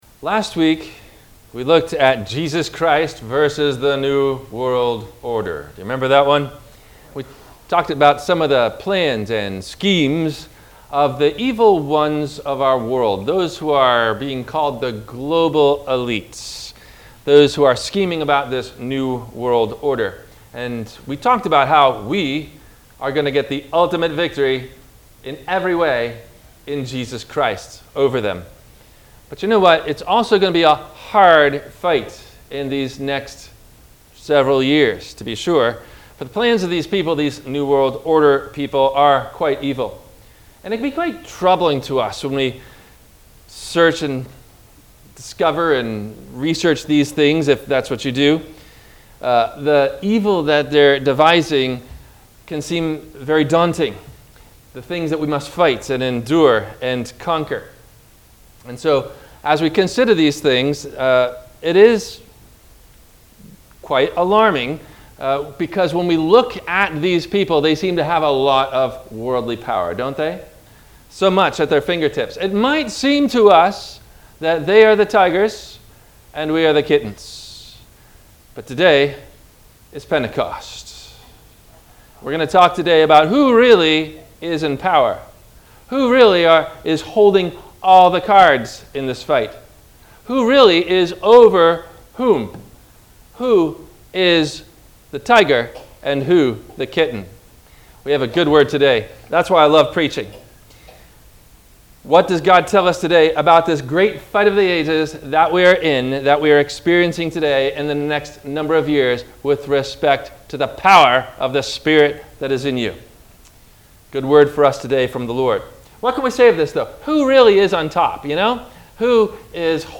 The Holy Spirit vs The New World Order – WMIE Radio Sermon – June 13 2022
WMIE Radio – Christ Lutheran Church, Cape Canaveral on Mondays from 12:30 – 1:00